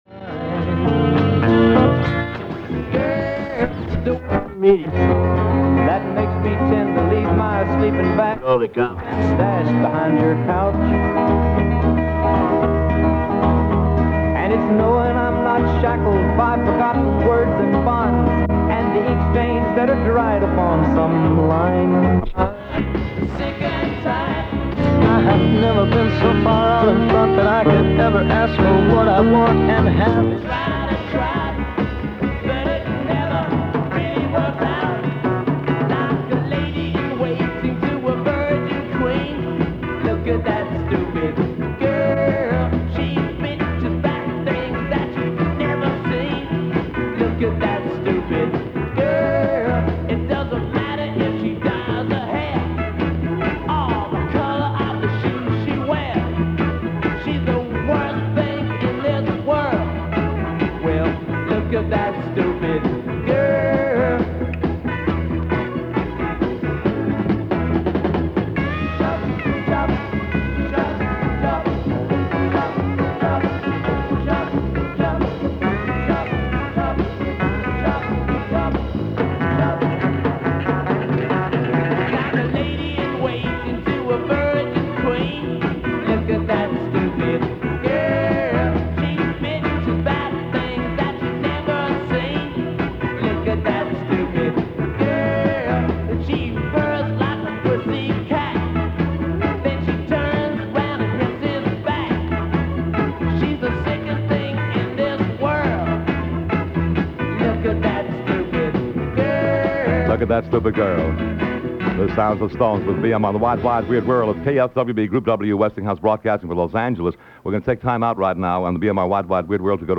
KFWB–KRLA–KHJ – various other stations during an LBJ Address -July 27, 1967